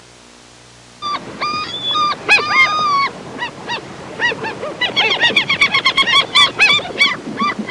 Crying Seagulls Sound Effect
Download a high-quality crying seagulls sound effect.
crying-seagulls.mp3